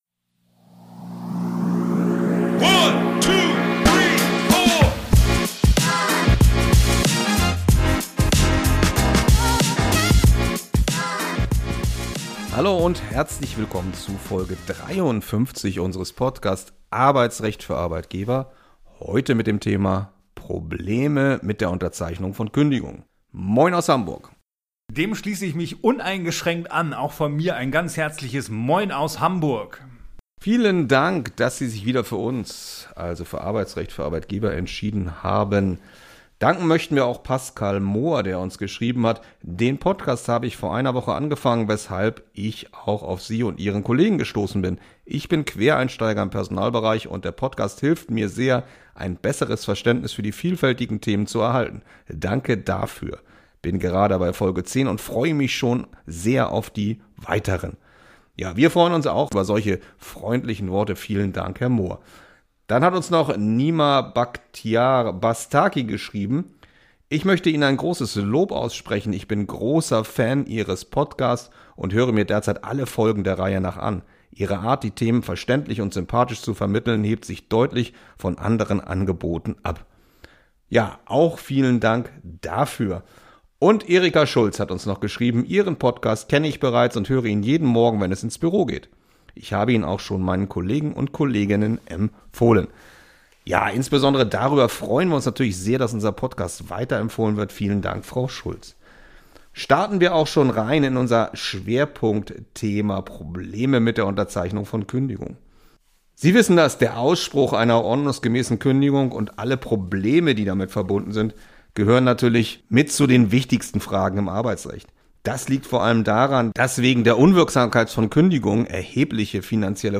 Für Arbeitgeber - neue Urteile, neue Gesetze, Praxistipps und grundlegende Informationen rund um alle arbeitsrechtlichen Fragestellungen. Zwei Fachanwälte für Arbeitsrecht